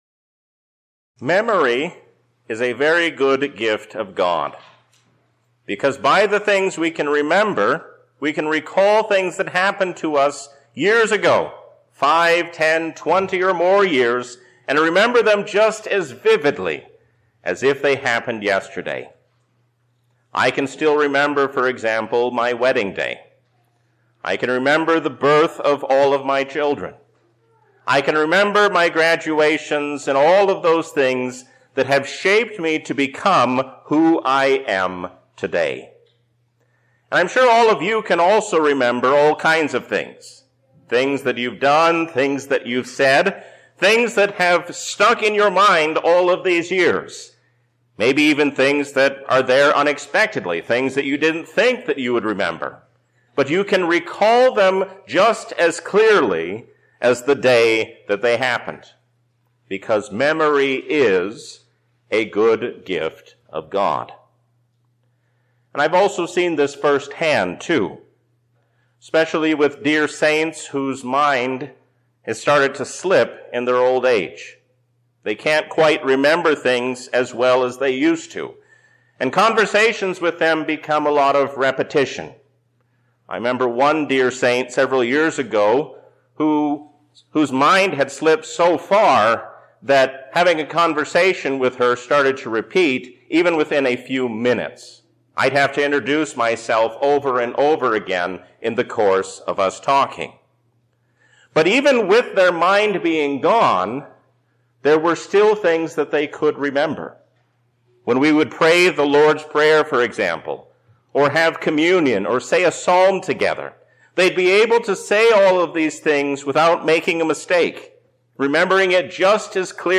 A sermon from the season "Lent 2024." Faith is as simple as trusting God because He is God.